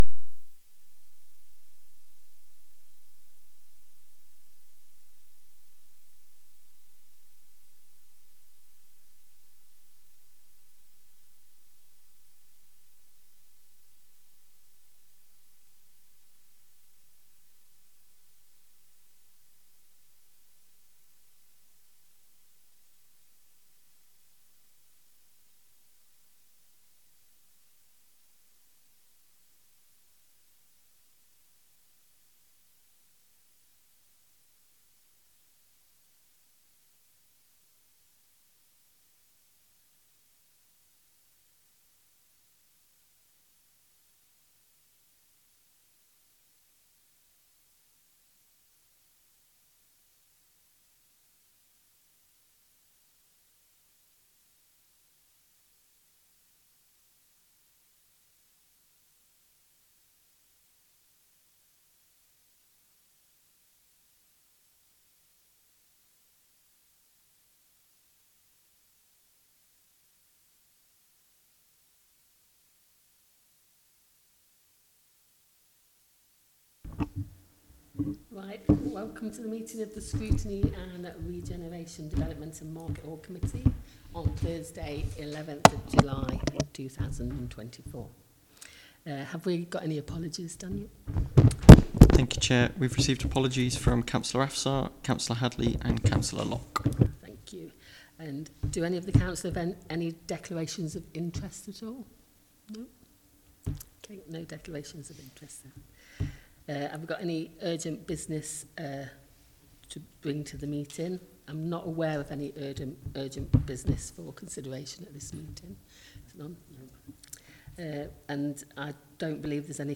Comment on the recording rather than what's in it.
Committee Scrutiny Regeneration Development and Market Hall Committee Meeting Date 11-07-24 Start Time 6.30pm End Time 6.50pm Meeting Venue Coltman VC Room, Town Hall, Burton upon Trent Please be aware that not all Council meetings are live streamed.